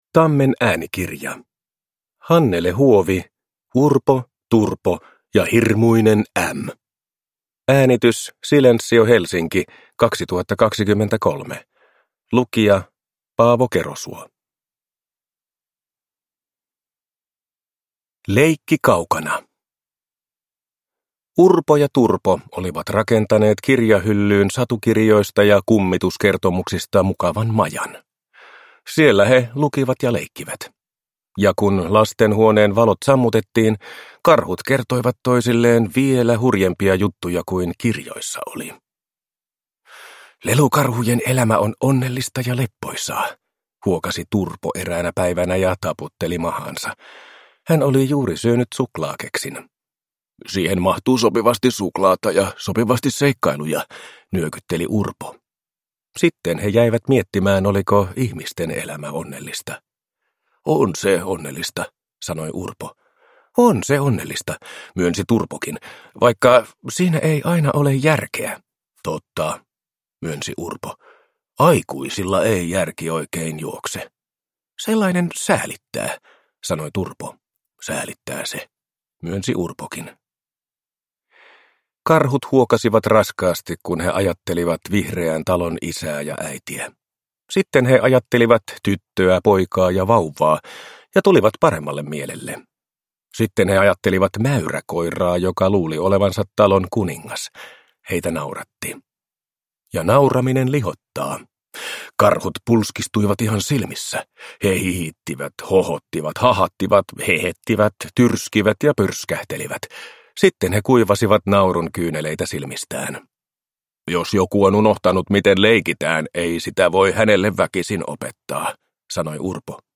Urpo, Turpo ja hirmuinen ÄM – Ljudbok – Laddas ner